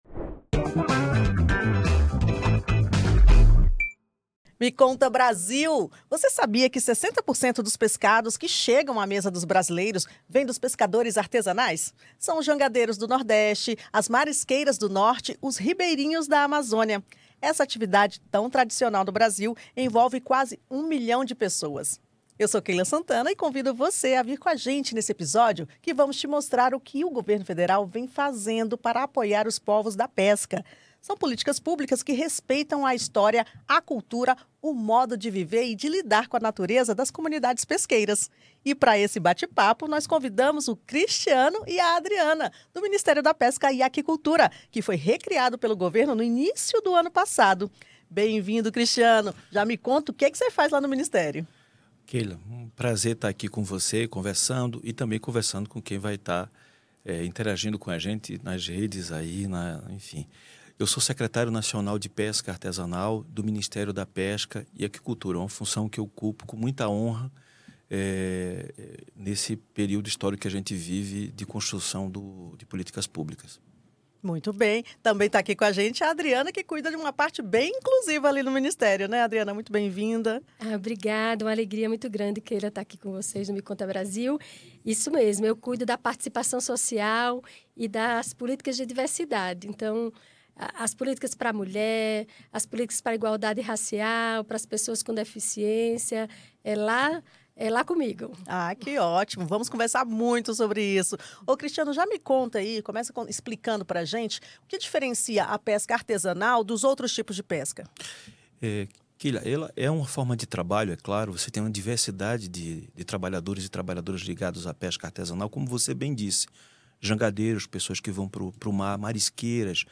Nesse episódio você vai saber tudo sobre o app Meu SUS Digital e também sobre como funciona a telessaúde. Para essa conversa, contamos com as especialistas Ana Estela Haddad, Secretária de Informação e Saúde Digital do Ministério da Saúde, e Paula Xavier, diretora do DATASUS.